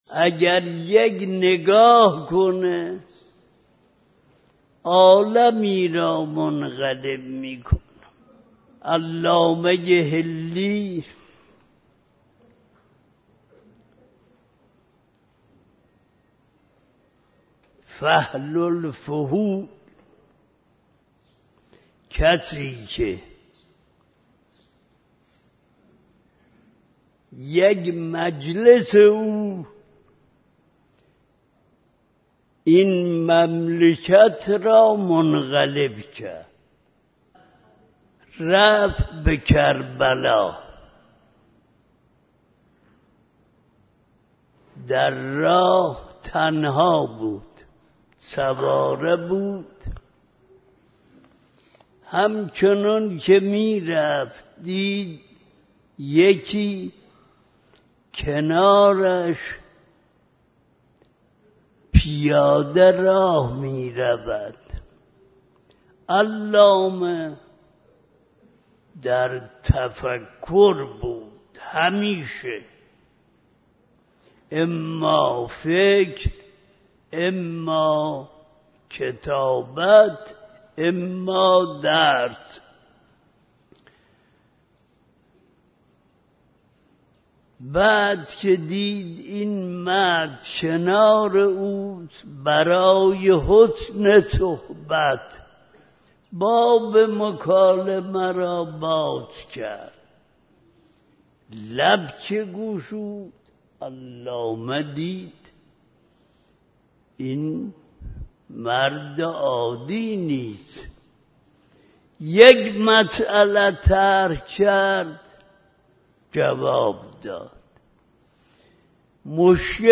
One glance of Imam-i Zaman (Imam of our time) remodels the world. Part of speech of Ayatollah wahid Khorasani If once he looks at this world, he will reshape it.